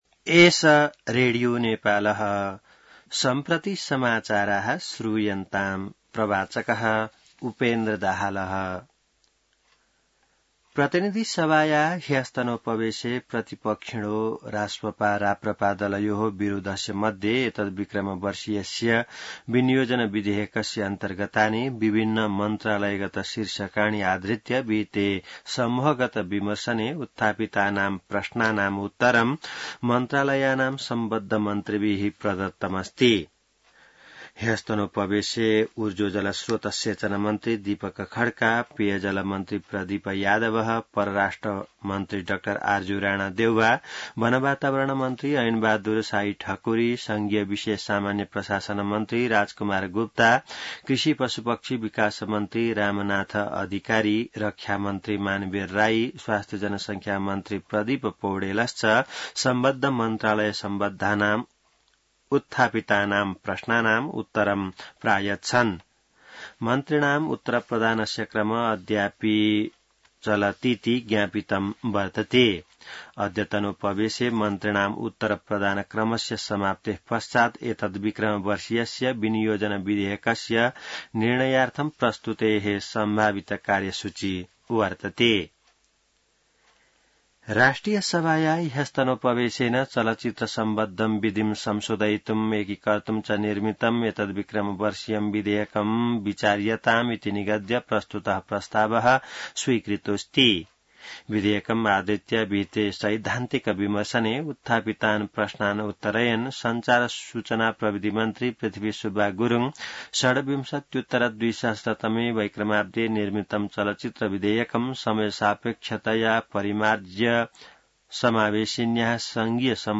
संस्कृत समाचार : १० असार , २०८२